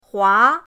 hua2.mp3